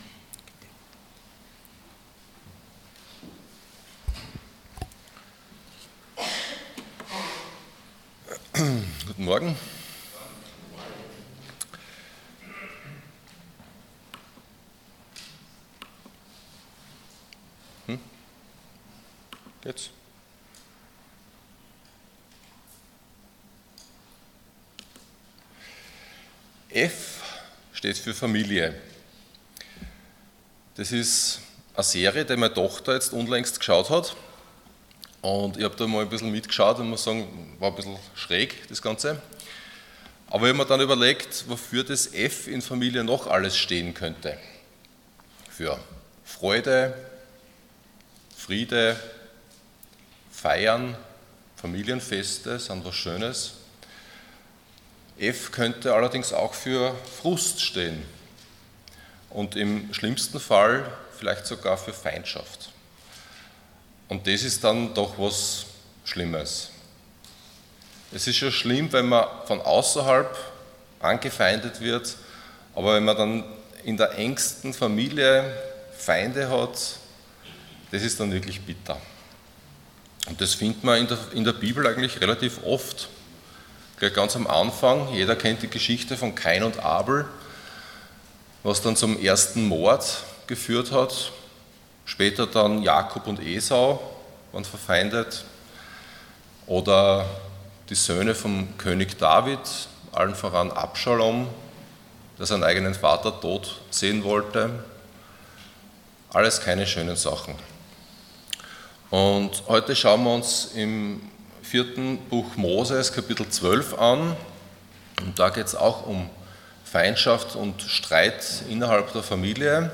15.06.2025 F steht für Familie Prediger